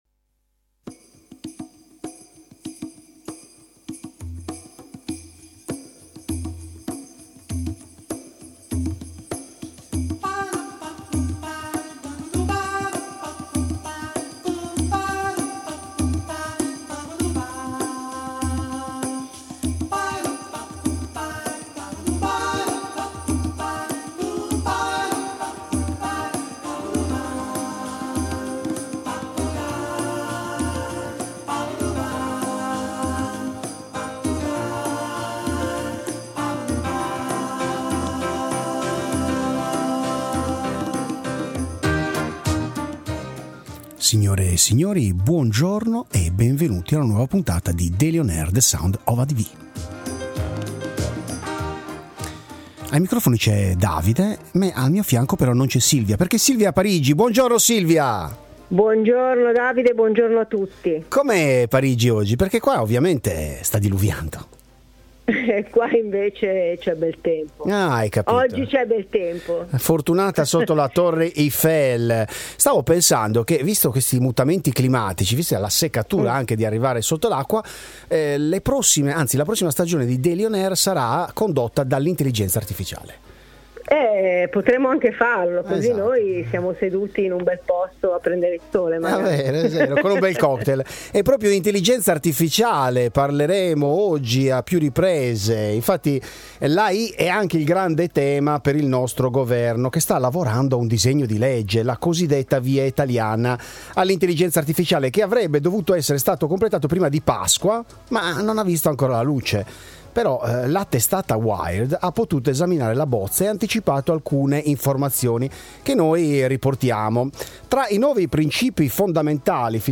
Verso il futuro: la GenAI che rimodella l’Asset e il Wealth Management, intervista